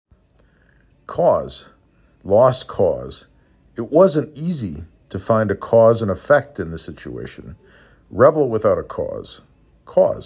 5 Letters, 1 Syllable
k o z
k aw z